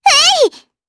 Estelle-Vox_Attack5_jp.wav